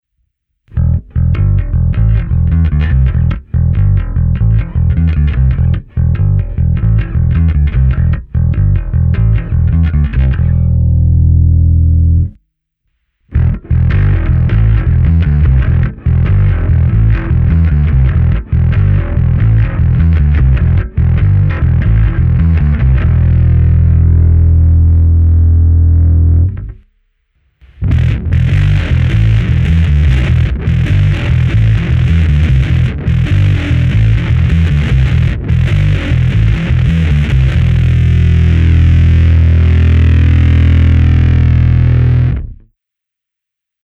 Overdrive je obecně nakřáplejší zkreslení, fuzz je oproti tomu výrazné, chlupaté zkreslení.
V následujících ukázkách je použitá baskytara Fender American Professional II Precision Bass V s roundwound strunami Sadowsky Blue Label ve výborném stavu. První část ukázky je čistý zvuk baskytary jen drobnou ekvalizací a kompresorem, v druhé části už je zařazeno zkreslení v režimu overdrive, kde jsem v maximální možné míře přimíchal čistý signál, ale ovladačem CLEAN TONE jsem z něj stáhnul maximálně výšky, zkrátka nastavení jako na fotkách výše, ve třetí části jsem nastavil výraznější fuzz, kde jsem naopak původní signál použil jen v nepatrné míře.